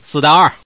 Index of /guizhou_ceshi_0/update/11363/res/sfx/ddz/man/